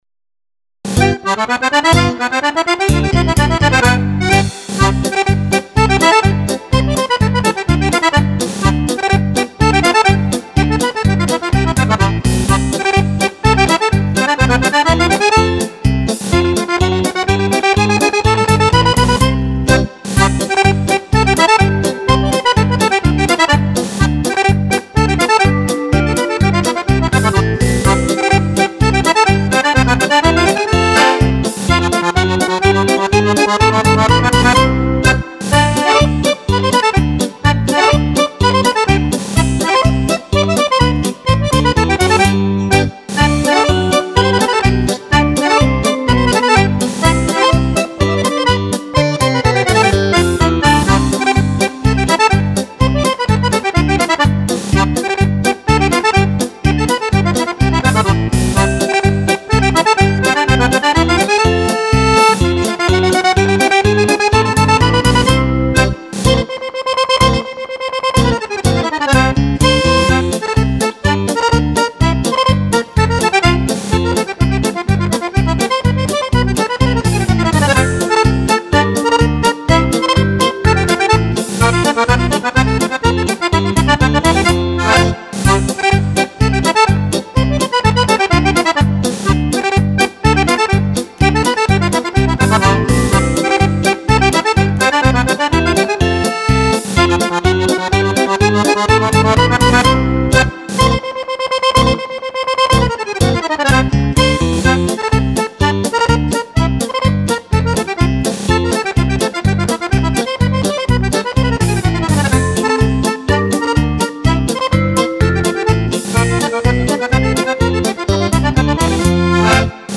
Polca
Polca per Fisarmonica